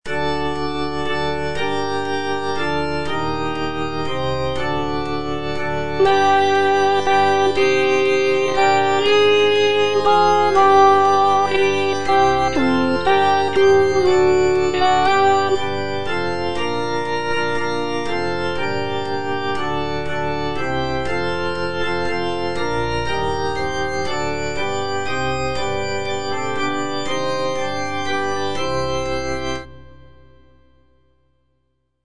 G.P. DA PALESTRINA - STABAT MATER Eja Mater, fons amoris (alto I) (Voice with metronome) Ads stop: auto-stop